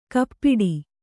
♪ kappiḍi